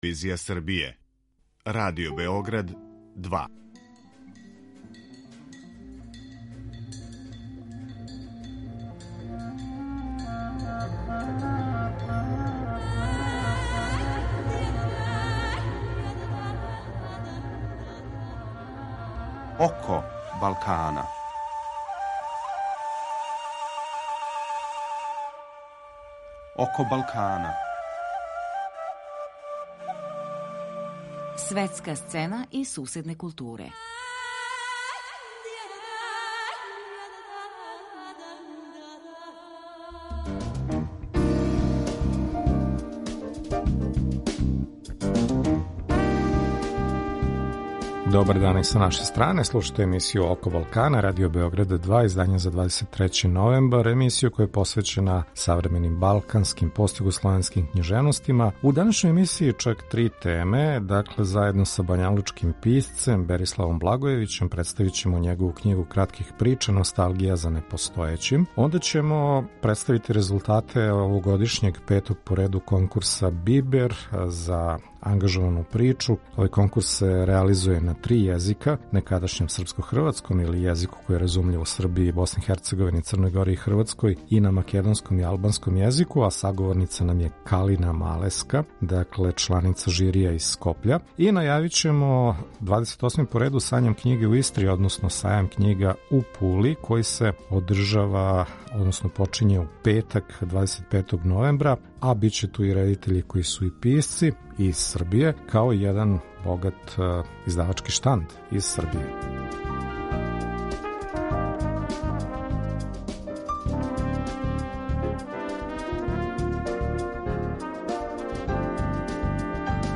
10:00 -> 02:14 Извор: Радио Београд 2 Аутор